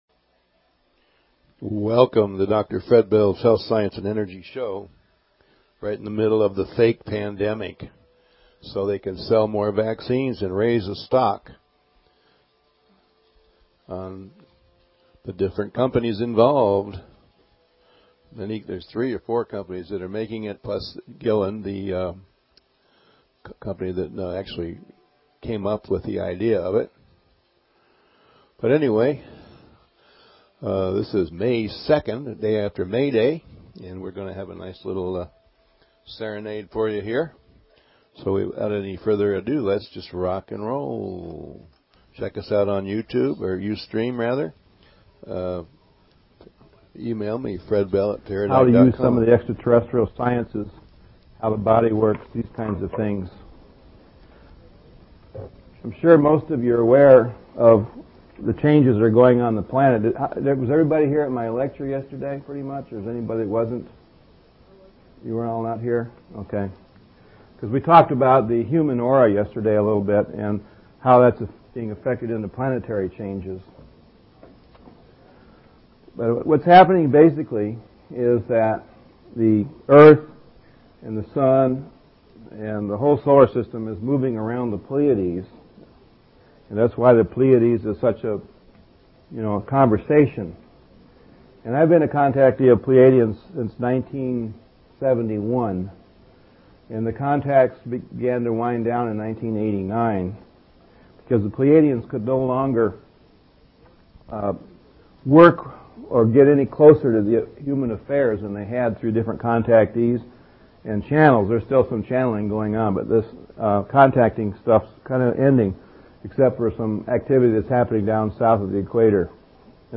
Talk Show Episode, Audio Podcast, Dr_Bells_Health_Science_and_Energy_Show and Courtesy of BBS Radio on , show guests , about , categorized as